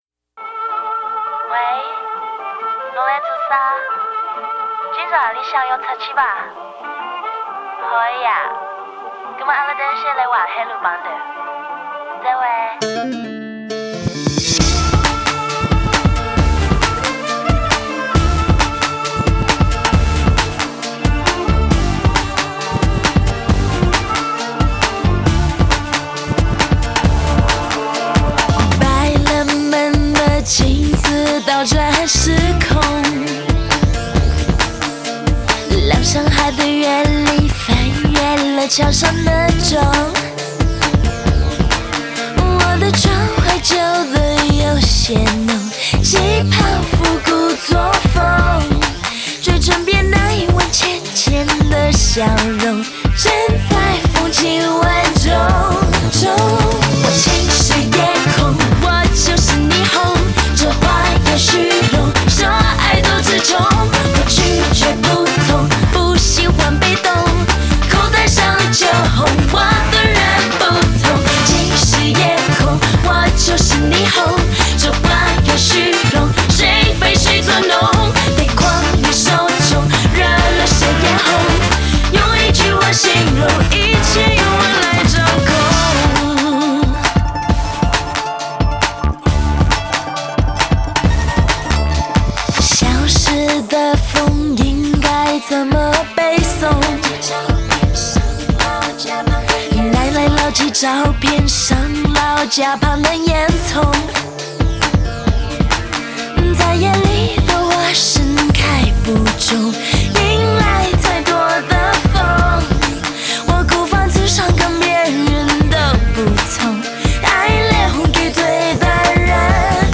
微风般的柔漾歌声、魔朵的迷人长腿、名媛级时尚品味
时空倒转老上海，复古作风正当红！
中国风混搭拉丁恰恰新舞流